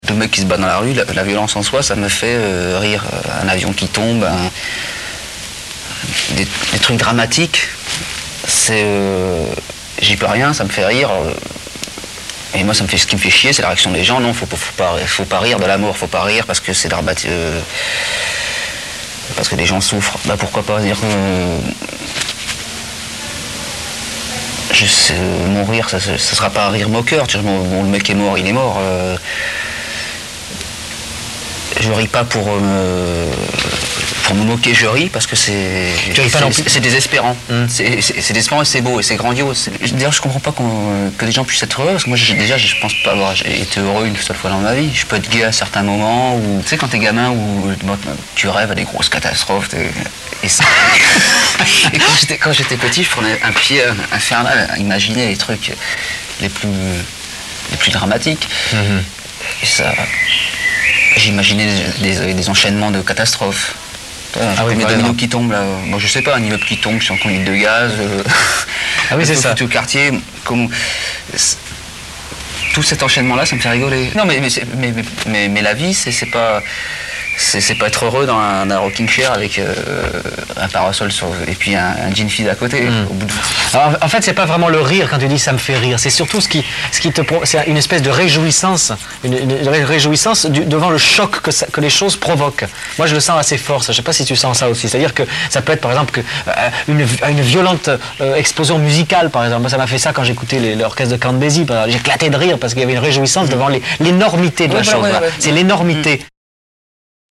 Excerpt from the movie Chronique de mon jardin, interview of Philippe Vuillemin